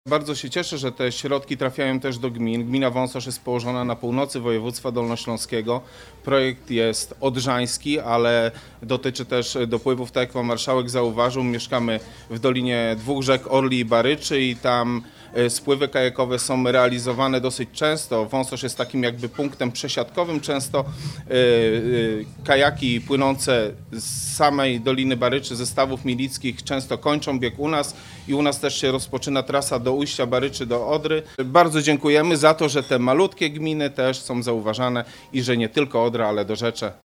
Burmistrz Wąsosza Paweł Niedźwiedź podkreślił znaczenie wsparcia finansowego dla mniejszych gmin w ramach projektów związanych z Odrą i jej dopływami.